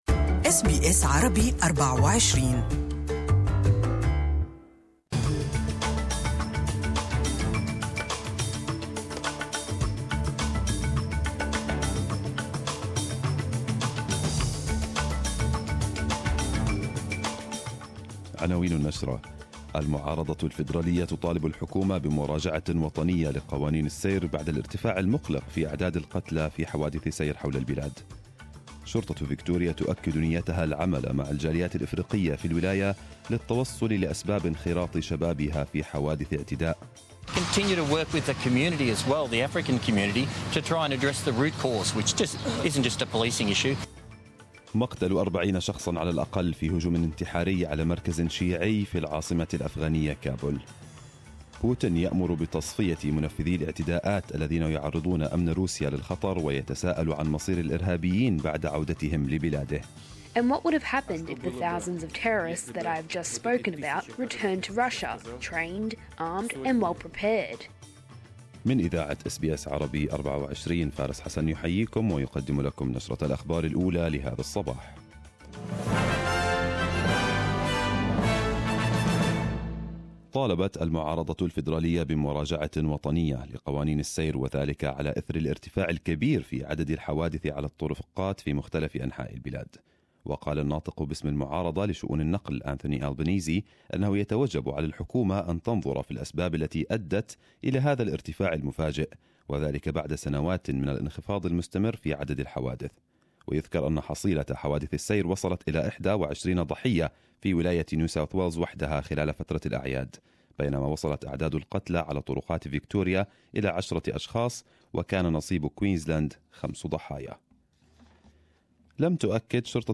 Arabic News Bulletin 29/12/2017.